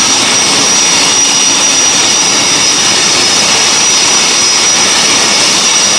engine-exhaust.wav